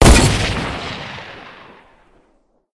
mgun2.ogg